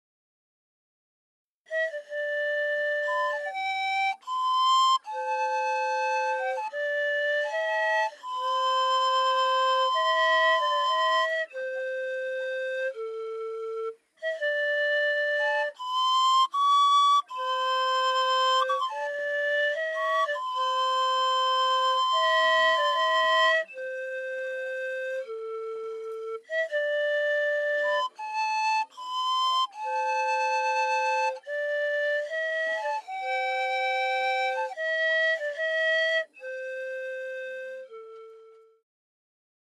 festive music played on simple bamboo panpipes, sets of which are distributed amongst the men at the New Year 624KB
Track 38 Padong panpipes.mp3